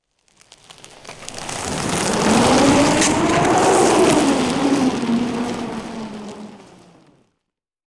• snow samples - stepping and crushing 4.wav
Collection of recordings on January 10th 2011 during the Georgia winter storm. Various sleds, ice breaking, ice creaking, icy tension cracks, and heavy snow crunches/impacts.
snow_samples_-_stepping_and_crushing_4_iU5.wav